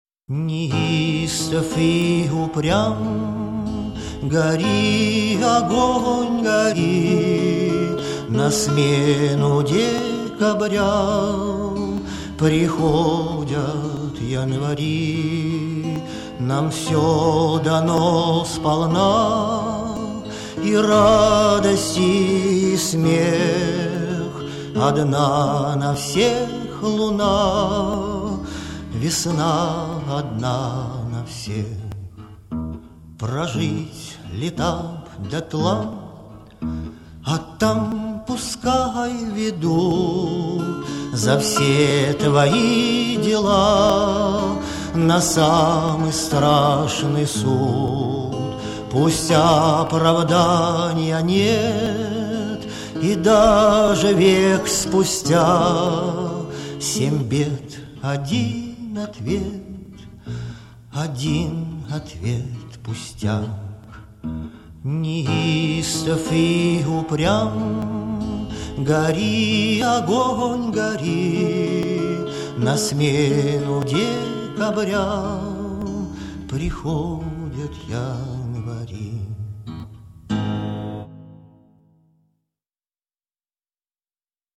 Да он и не смог бы – очень голос у него мягкий.